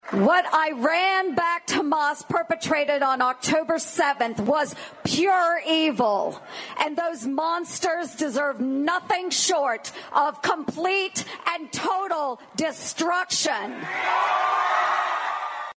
U-S SENATOR JONI ERNST ADDRESSED THE TENS OF THOUSANDS TUESDAY WHO GATHERED IN WASHINGTON, D.C. FOR THE MARCH FOR ISRAEL.
(CROWD FADE) :15